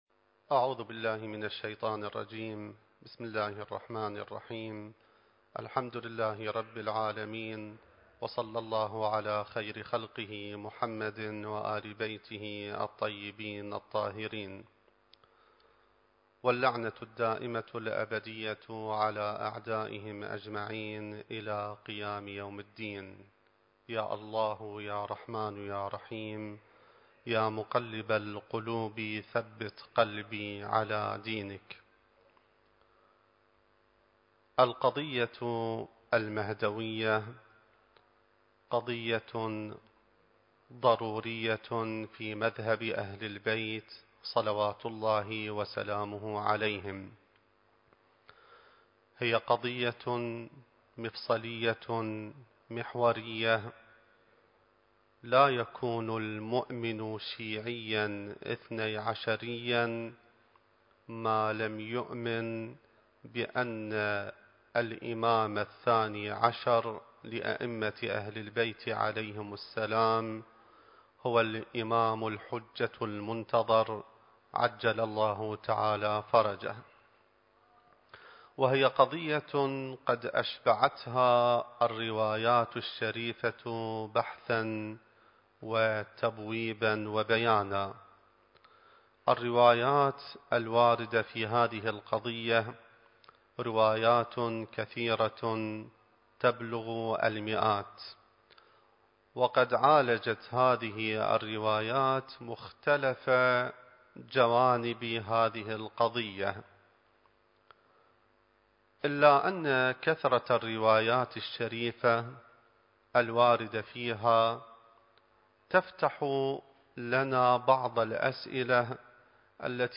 المكان: العتبة العلوية المقدسة الزمان: ذكرى ولادة الإمام المهدي (عجّل الله فرجه) التاريخ: 2021